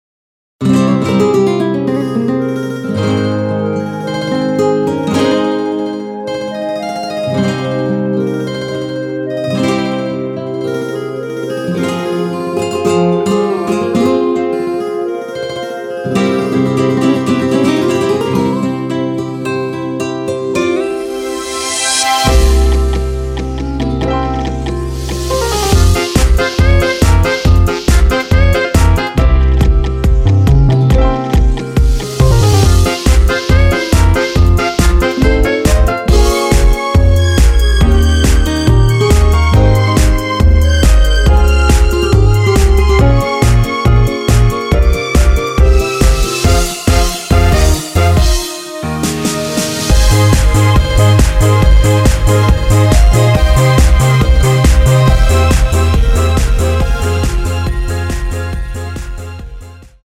대부분의 남성분이 부르실수 있는키의 MR입니다.
원키에서(-9)내린 멜로디 포함된 MR입니다.
Fm
앞부분30초, 뒷부분30초씩 편집해서 올려 드리고 있습니다.
중간에 음이 끈어지고 다시 나오는 이유는